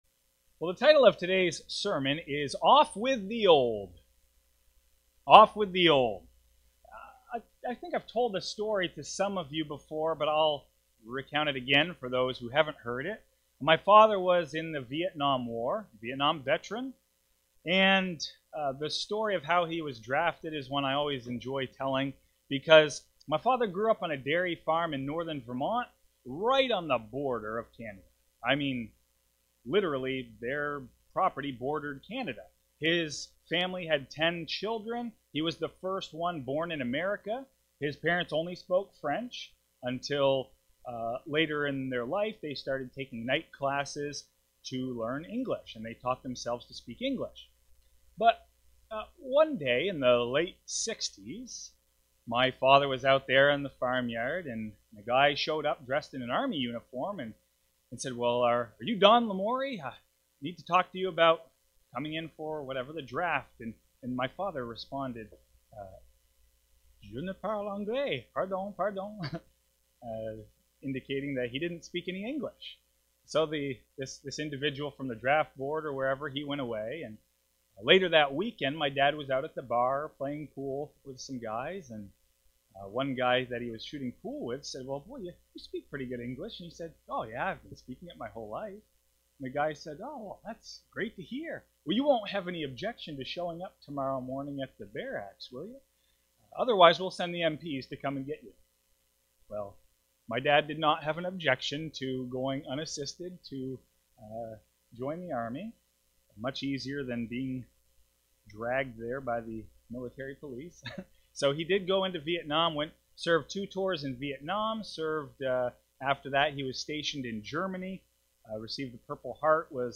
Given in Fargo, ND